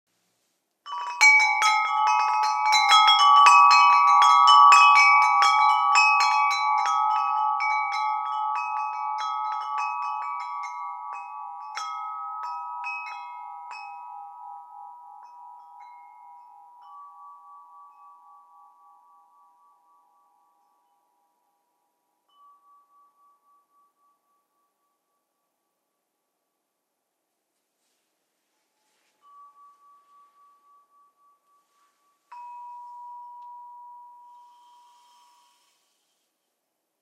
Feature 1: Soft and soothing soundFeature 2: Long-lasting resonanceFeature 3: For meditation, relaxation, therapy applications, or even for decoratin…